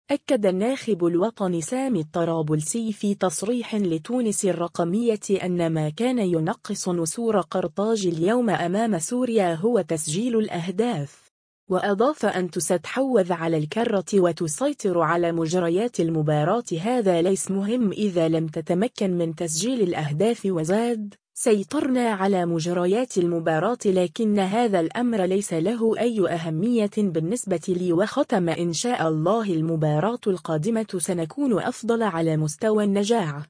أكّد الناخب الوطني سامي الطرابلسي في تصريح لتونس الرقمية أنّ ما كان ينقص نسور قرطاج اليوم أمام سوريا هو تسجيل الأهداف.